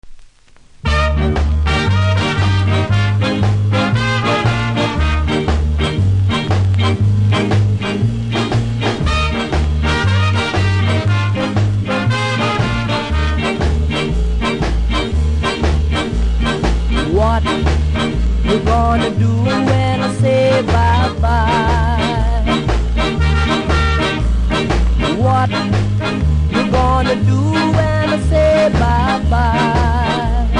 キズ多めですが音はそれほどでもないので試聴で確認下さい。